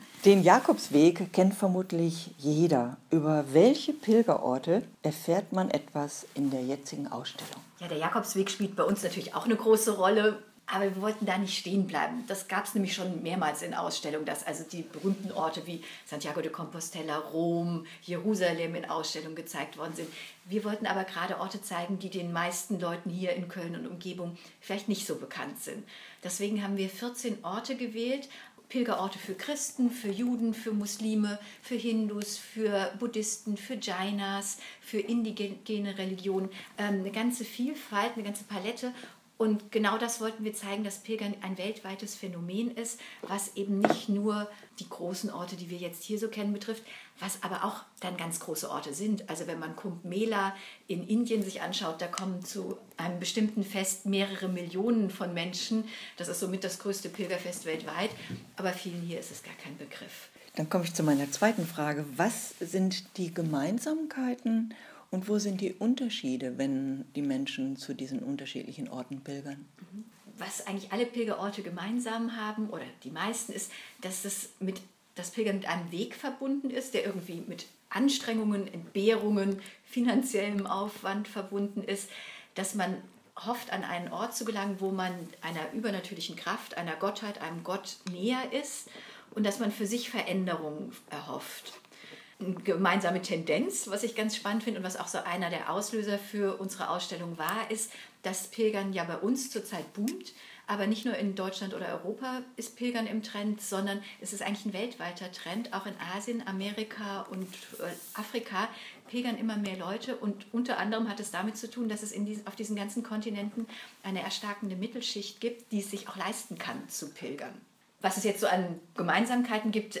interview-pilgern.wav